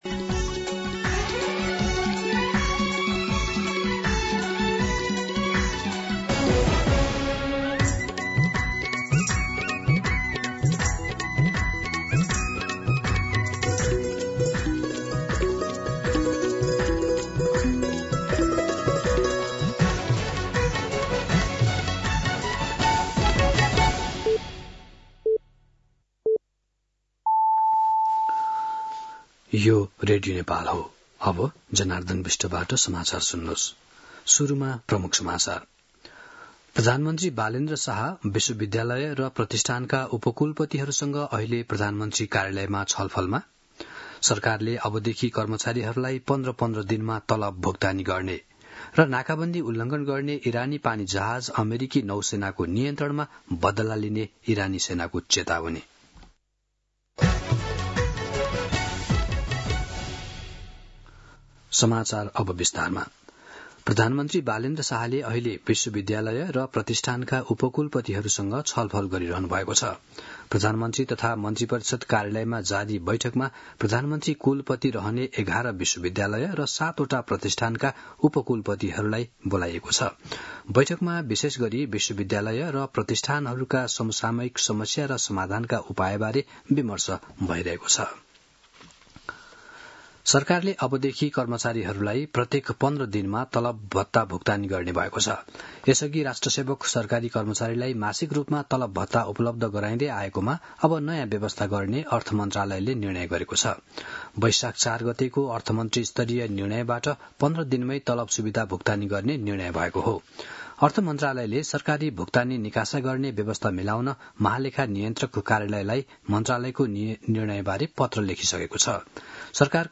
An online outlet of Nepal's national radio broadcaster
दिउँसो ३ बजेको नेपाली समाचार : ७ वैशाख , २०८३
3pm-News-07.mp3